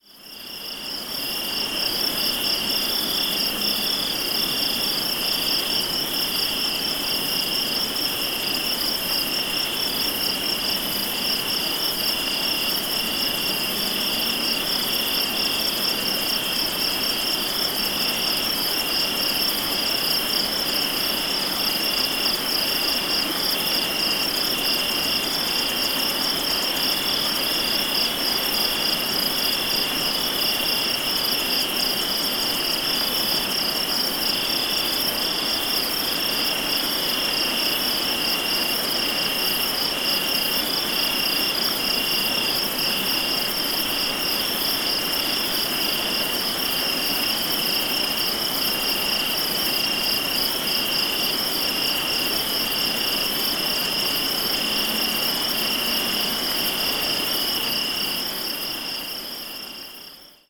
Ambiente de campo con grillos 2
ambiente
grillo
Sonidos: Animales
Sonidos: Rural